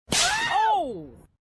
Slap Oh funny sound effect sound effects free download